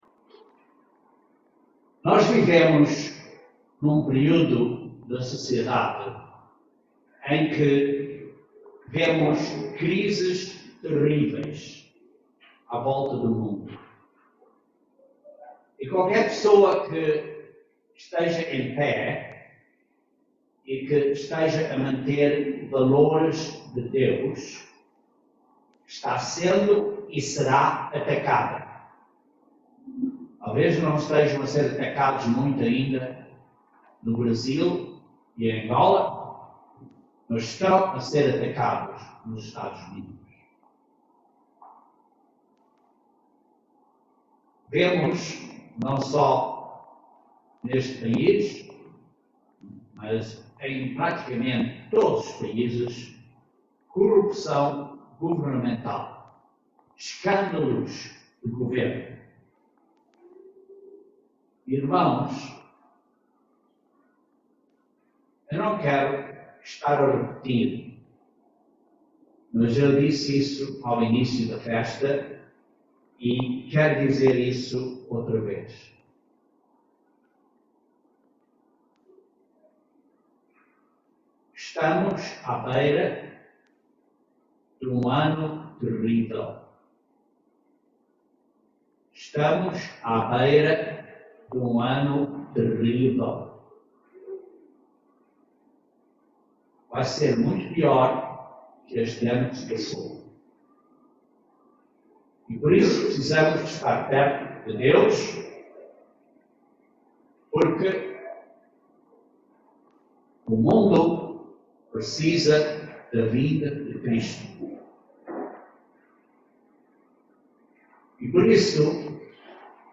Essa reconciliação começa em casa, entre marido e mulher, família, cidadões da nação e finalmente entre as nações. Este sermão aborda princípios bíblicos de reconciliação baseados em Mateus 18:15.